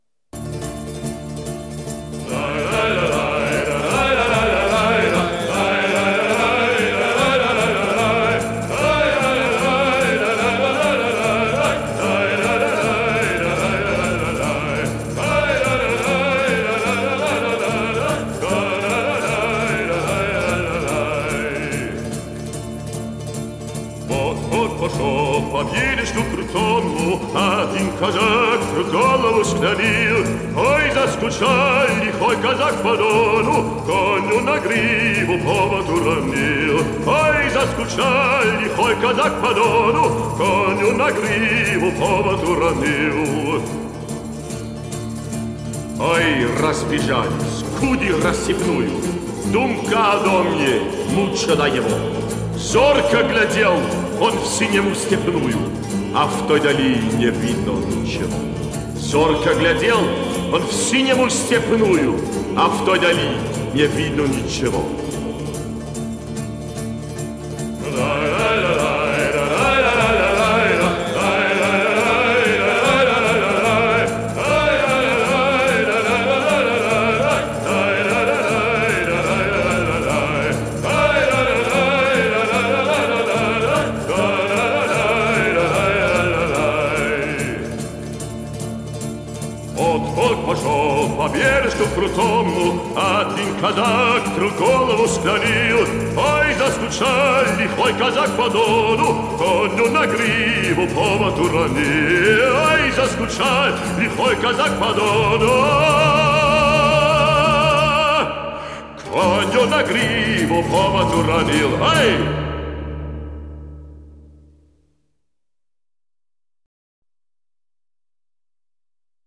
Другая интерпретация песни, в более традиционной манере.